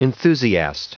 Prononciation du mot enthusiast en anglais (fichier audio)
Prononciation du mot : enthusiast